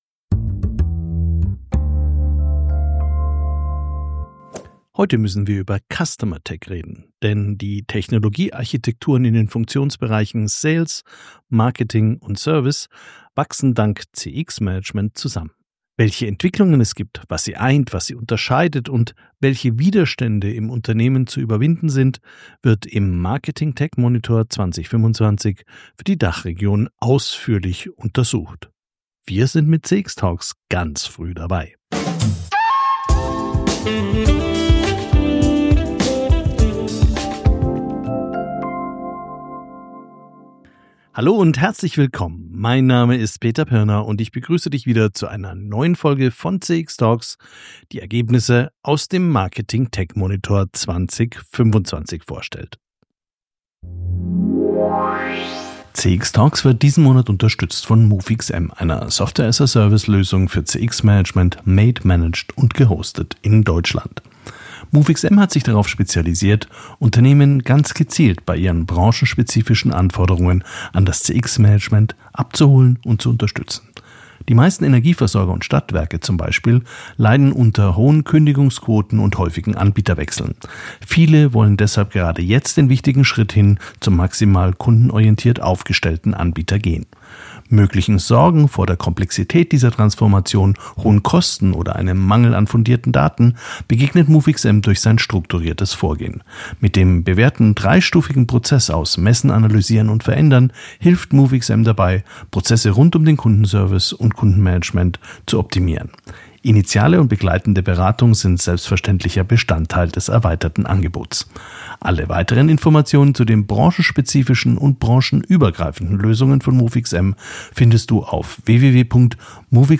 Wir sprechen also heute über Technik oder viel mehr über das, was Technik mit Unternehmen macht – und manchmal nicht machen kann. Und wir sprechen über den Einfluss von Customer Experience Management auf all die großen und kleinen Entscheidungen, die in der IT und in den Fachbereichen zu treffen sind.